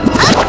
FXREVERSE2-R.wav